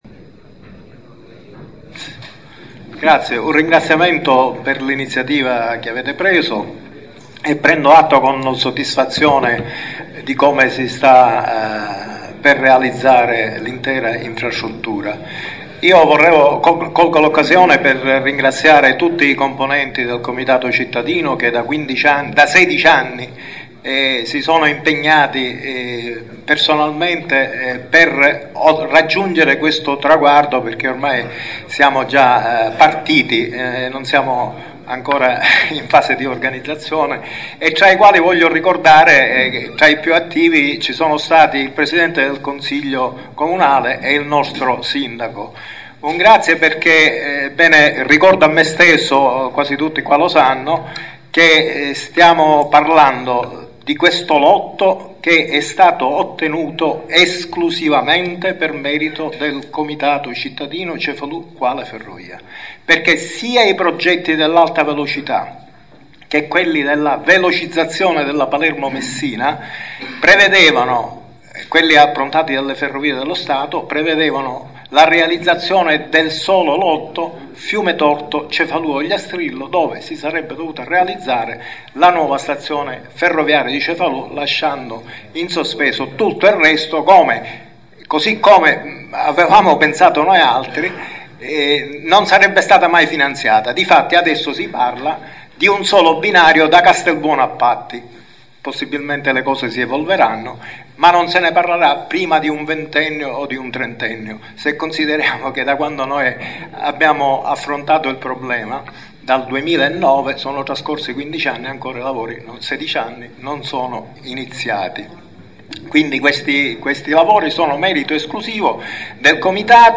Si è svolto  a Cefalù giovedì 26 novembre alle ore 16, presso la sala consiliare del comune di Cefalù, il primo degli incontri preannunciati dal Sindaco con i vertici delle società interessate alla realizzazione dei lavori del raddoppio della linea ferroviaria e della costruzione della nuova stazione ferroviaria di Cefalù.